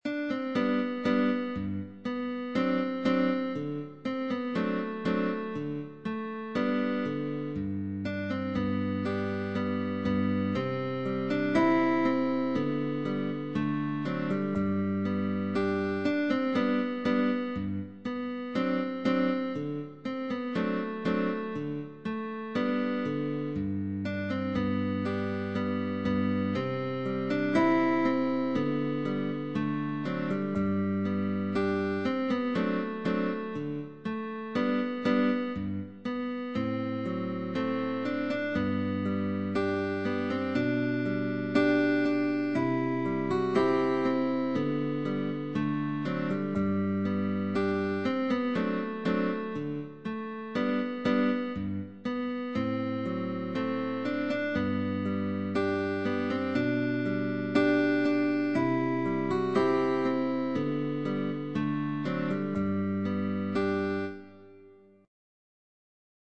Aria, fragment of Mozart's opera "The magic Flute" by guitar duo: Pupil and teacher.
LET’S PLAY TOGETHER – GUITAR DUO: PUPIL and TEACHER Fragment of Mozart’s opera “The magic Flute” Rest Stroke, First Position. Slurs.